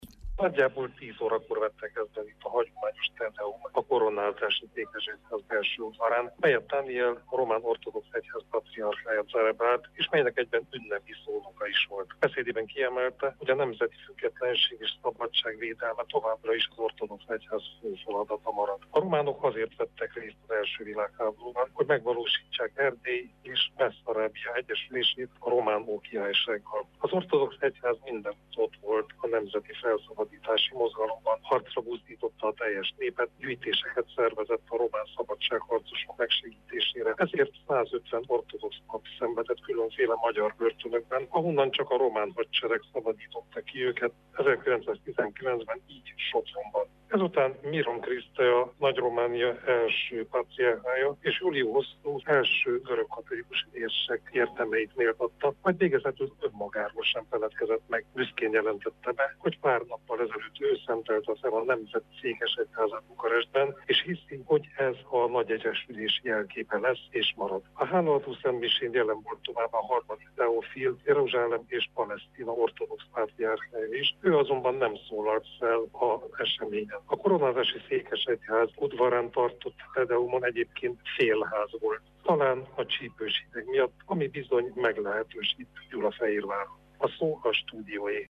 Gyulafehérvárról tudósítunk